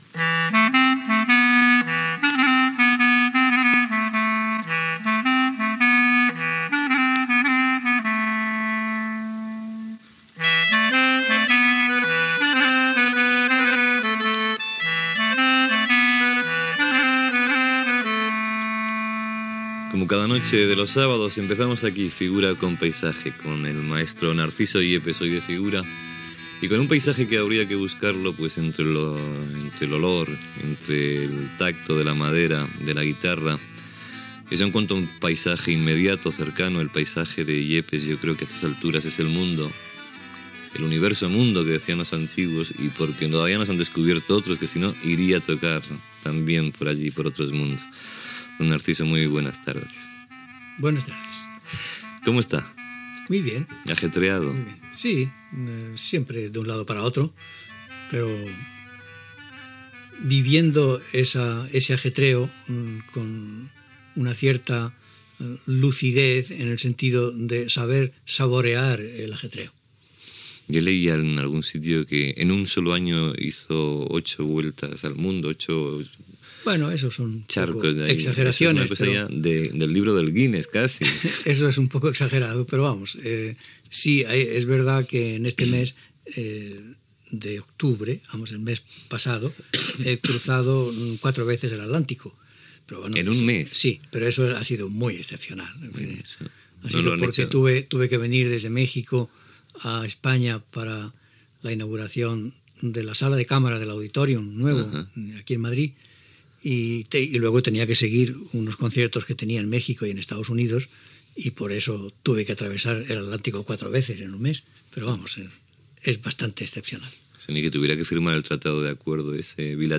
Sintonia del programa, presentació i entrevista al guitarrista Narciso Yepes sobre els seus viatges i la seva afició als escacs.